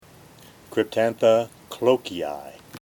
Pronunciation/Pronunciación:
Cryp-tán-tha  clò-ke-yi